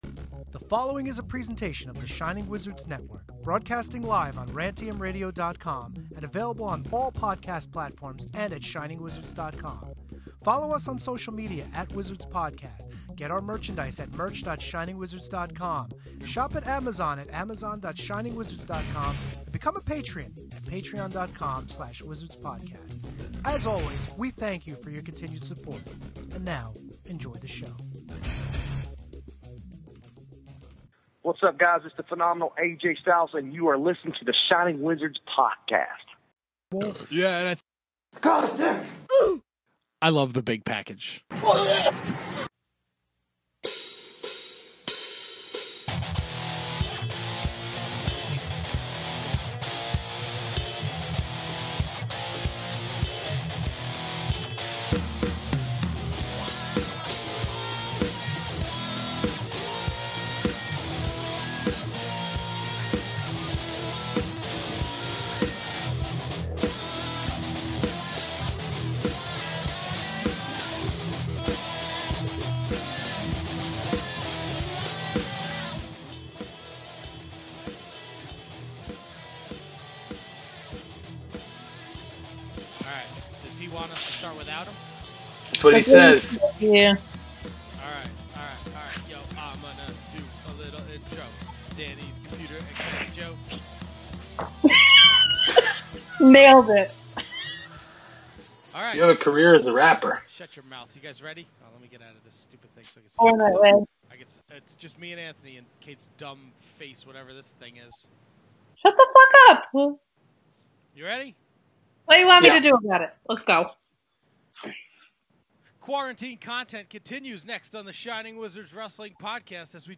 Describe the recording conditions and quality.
fire up the Skype and talk about the latest episode of AEW Dynamite.